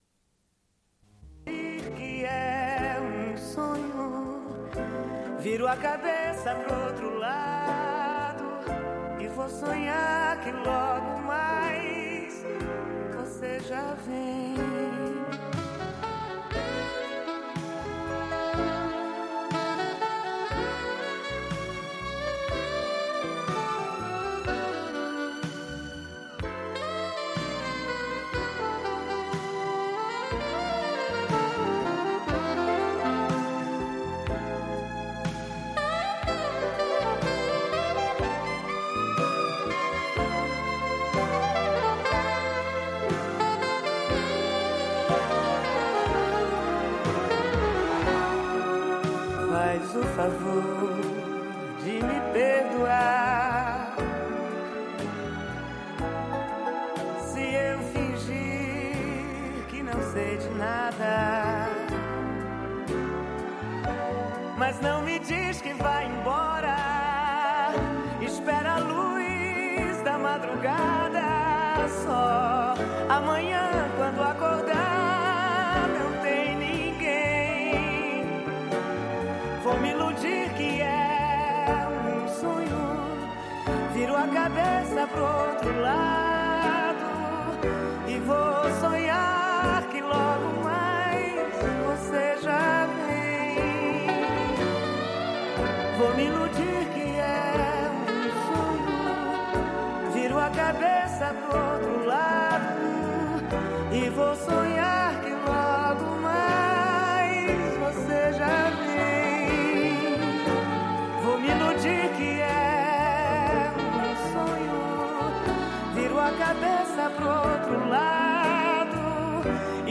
Une cassette audio, face B00:46:47
Diffusion d'un entretien en différé.